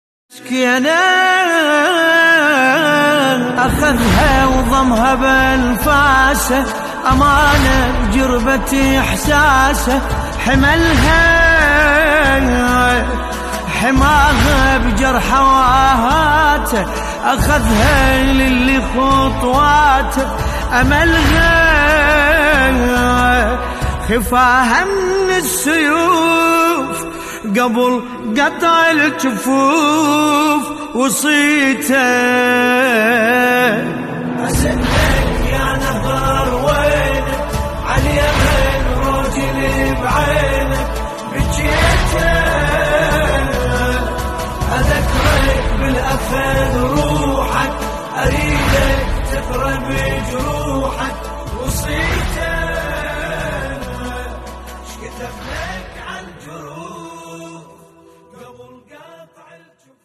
نوحه عربی محرم ۹۵